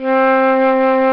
Flute Lo Sound Effect
Download a high-quality flute lo sound effect.
flute-lo.mp3